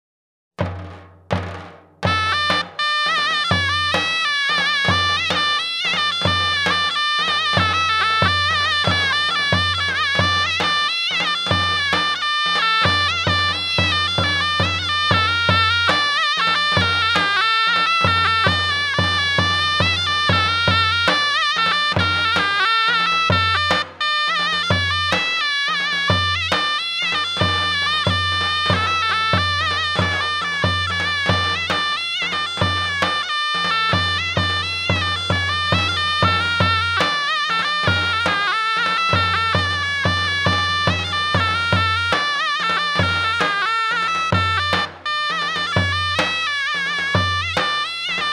Sözlü, Sözsüz Yöresel Müzikler